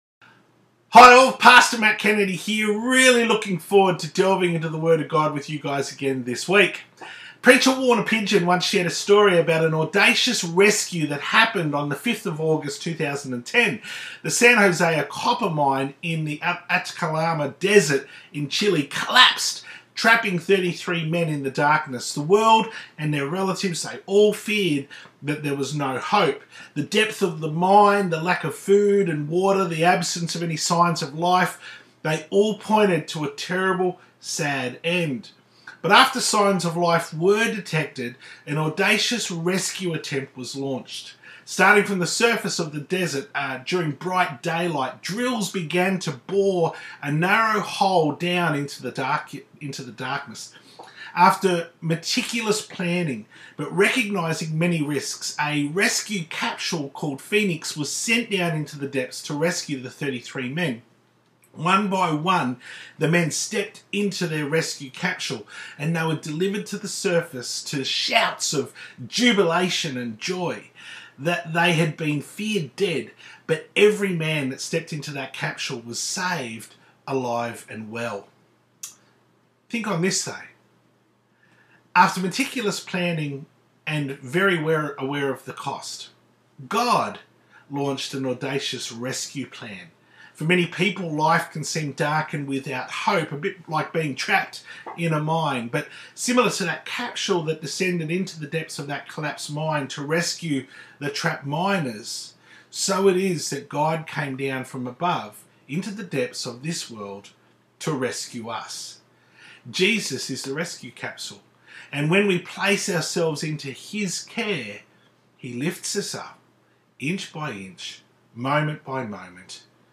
Sermon_ Undaunted ‘Part 9’